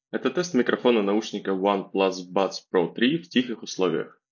Микрофон OnePlus Buds Pro 3 на 8 из 10, немного подкачал в шумных условиях.
В тихих условиях все на уровне.
В тихих условиях:
oneplus-buds-pro-3-tiho.m4a